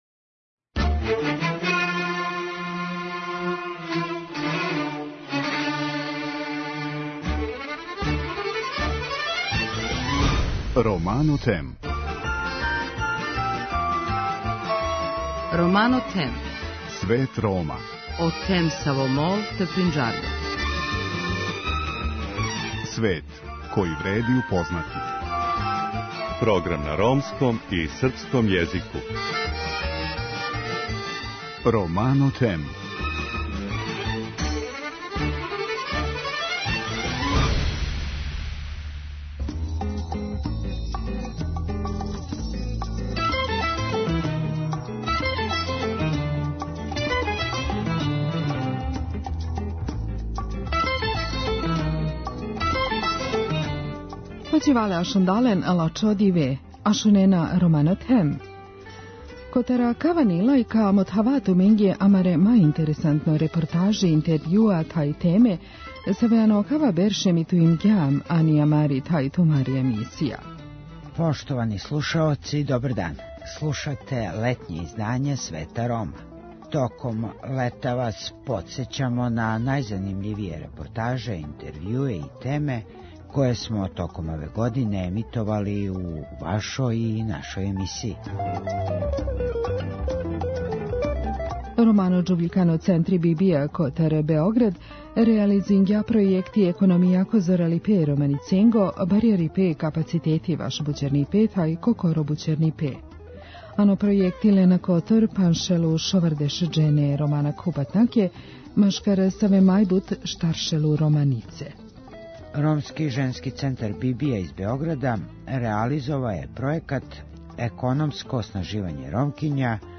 У наставку, чућете репортажу о животу Рома за време пандемије из бугарског града Сливен.